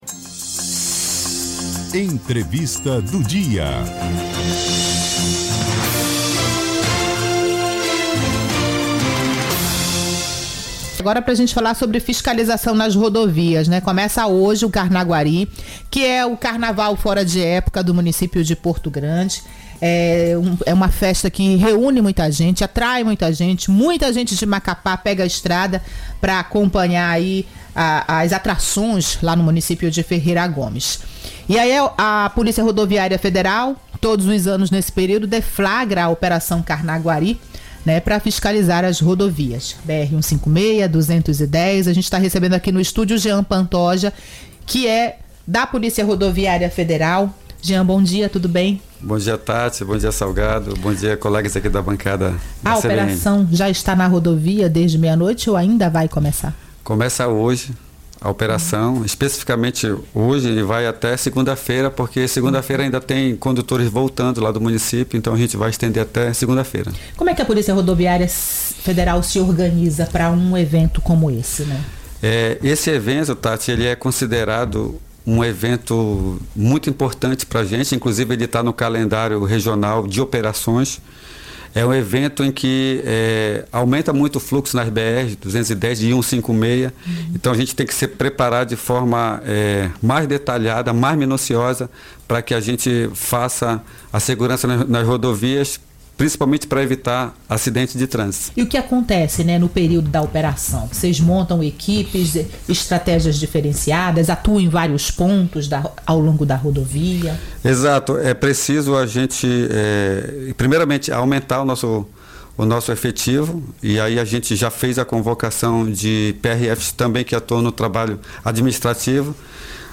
ENTREVISTA-OPERAO-CARNAGUARI-PRF.mp3